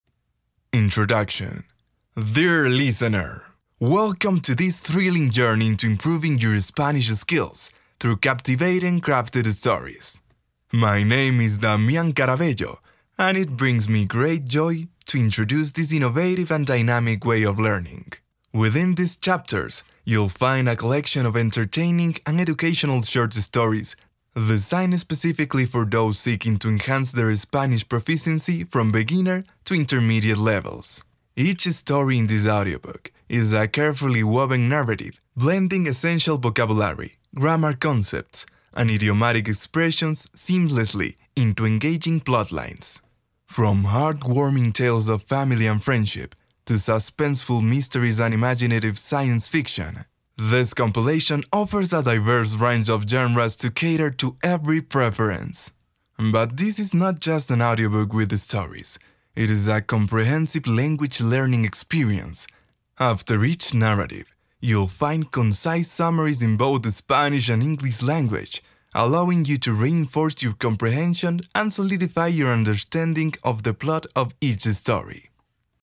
Échantillons de voix en langue étrangère
Démo commerciale